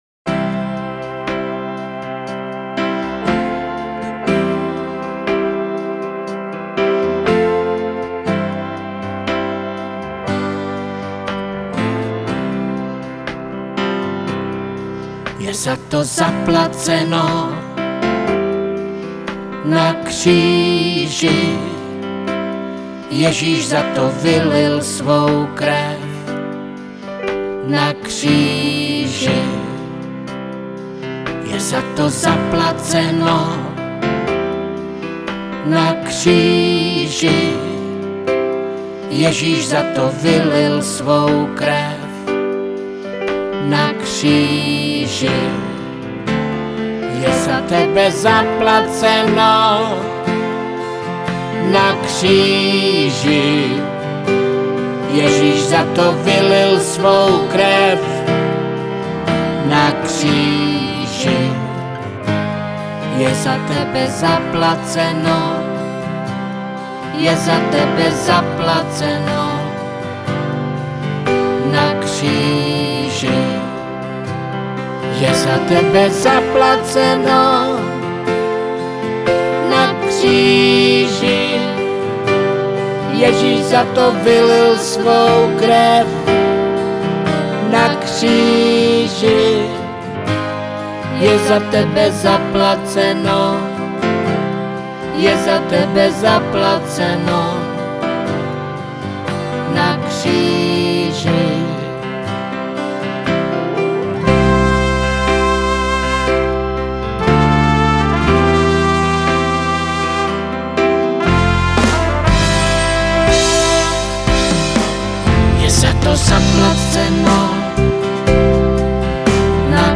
Křesťanské písně